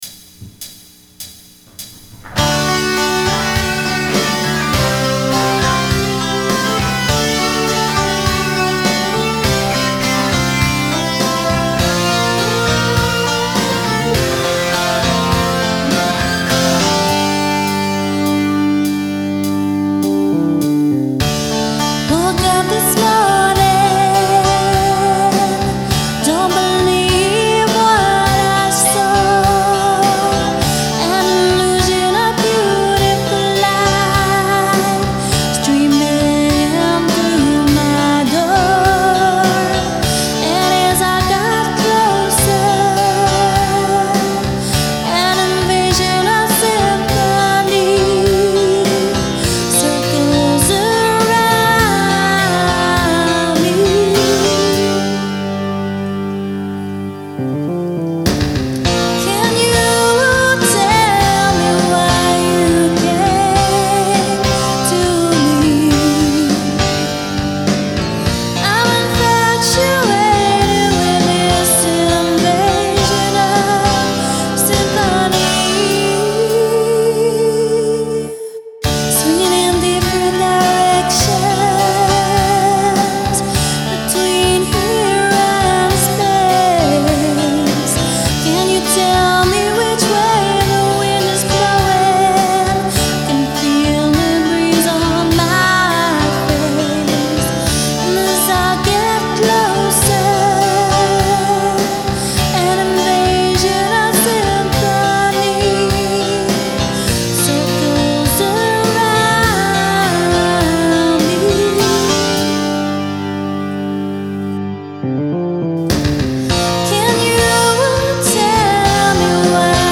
New track with my great new singer!
I'm in Cali, she's in Ireland, all done via e-mailed WAV files